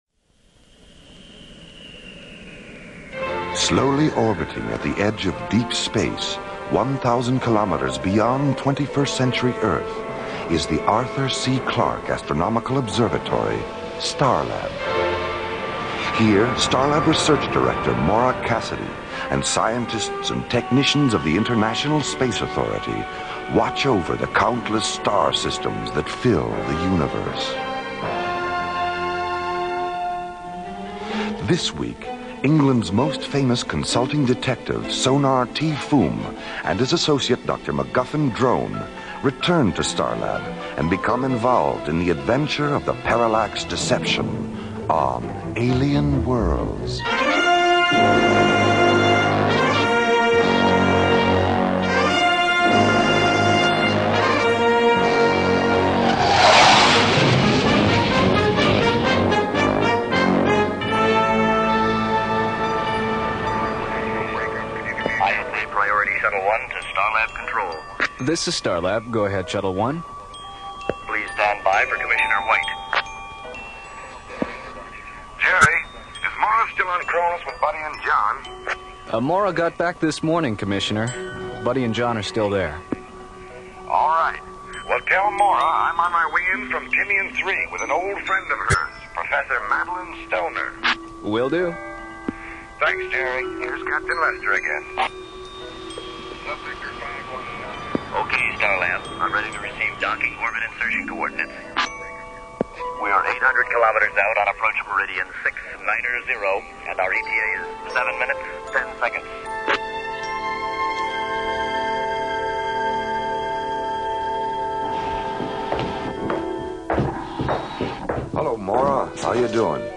'Alien Worlds' was a syndicated radio show that brought together a blend of captivating narratives, realistic sound effects, and high production values, setting a new standard for audio drama.
The show was ahead of its time, utilizing a documentary style of dialogue that immersed listeners in its interstellar adventures. Each episode transported the audience to different corners of the galaxy, exploring complex themes and introducing memorable characters.